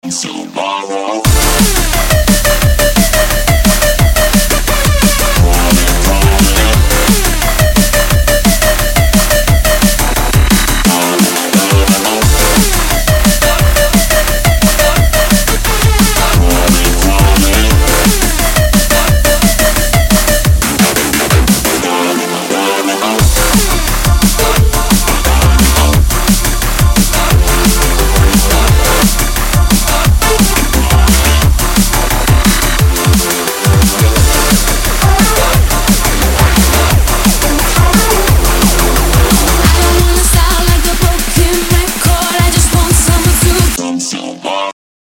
• Качество: 128, Stereo
drum&bass
Стиль: Drum and Bass